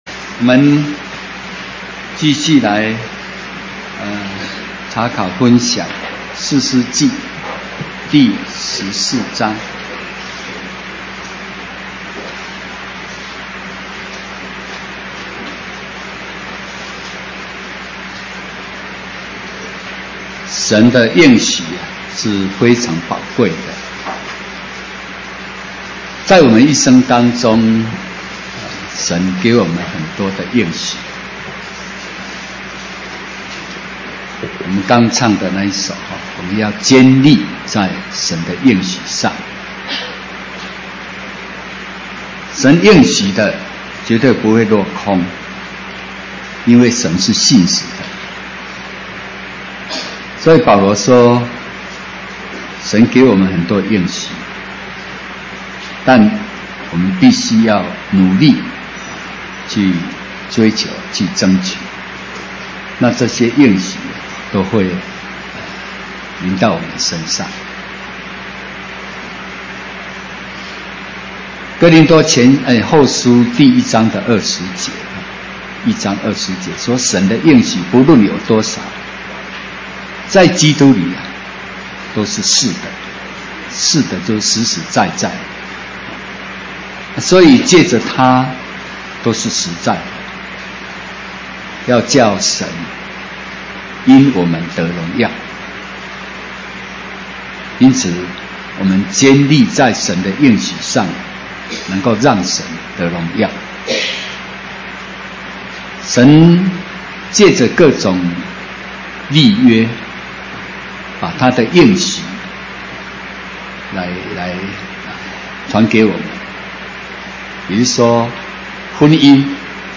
講習會